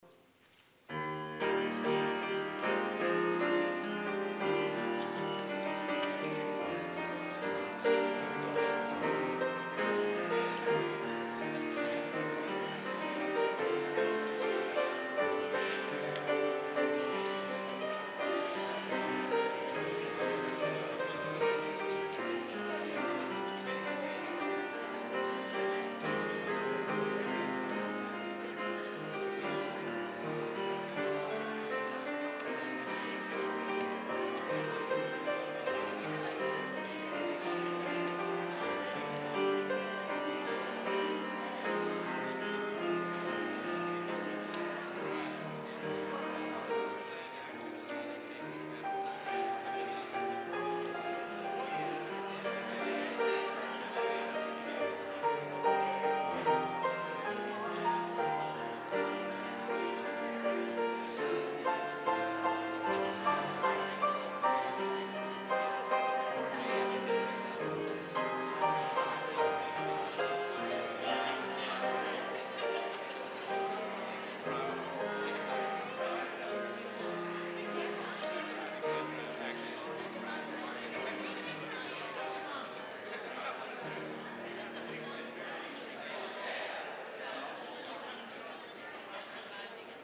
organ